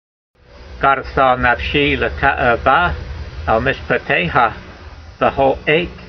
Note: Sephardim enunciate a “dsound sometimes after resh, as you may notice in the word for “breaks”--gar(d)esah.